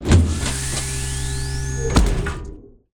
alien_gate.ogg